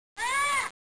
parrot.wav